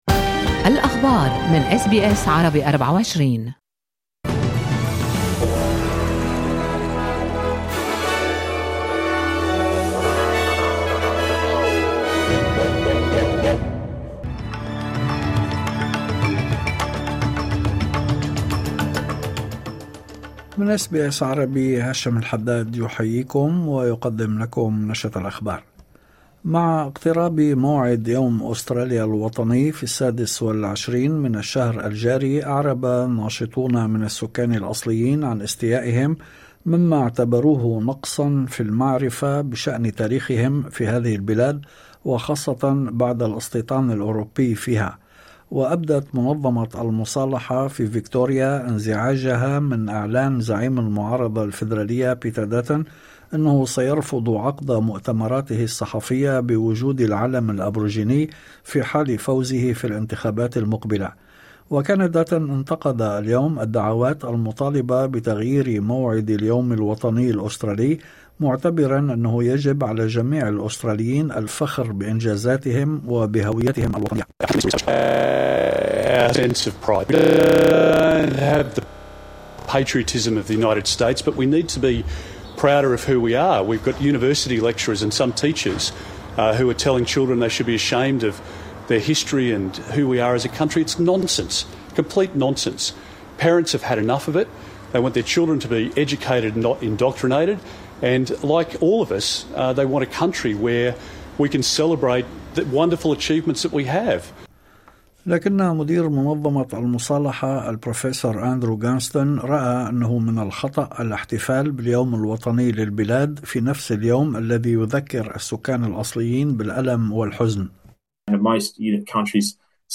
نشرة أخبار الظهيرة 24/01/2025